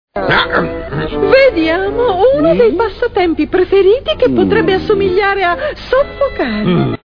dal cartone animato "Zombie Hotel", in cui doppia Funerella.